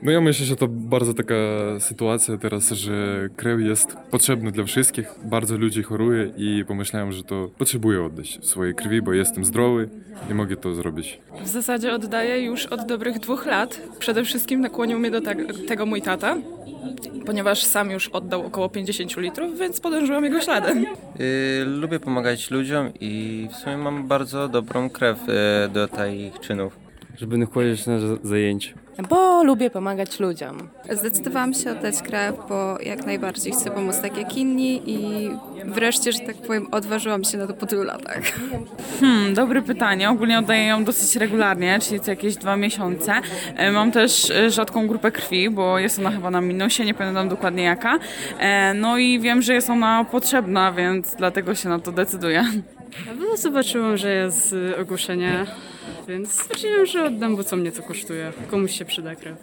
Studenci zapytani, dlaczego postanowili oddać swoją krew odpowiadali następująco: Wypowiedzi dawców krwi Postanowiłam oddać krew, bo przecież nic mnie to nie kosztuje, a może komuś pomóc – mówi jedna ze studentek.